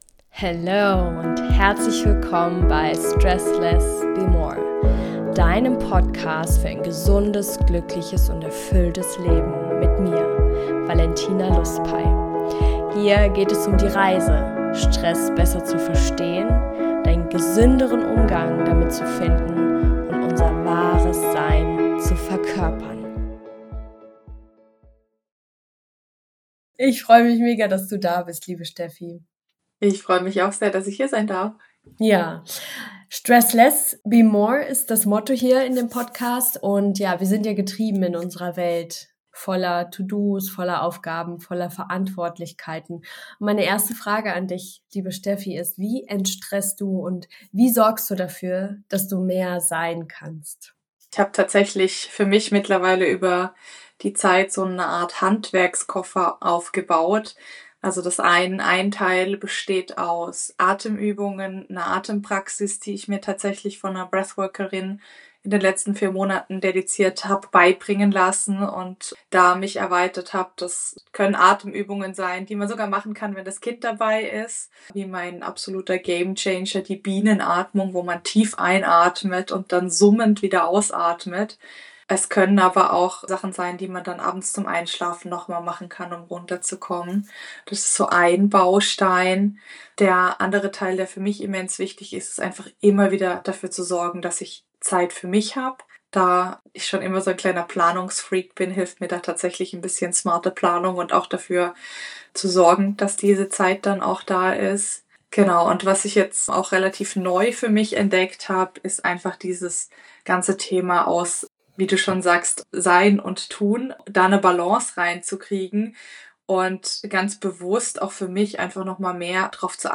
In dieser Episode erwartet dich ein inspirierendes Gespräch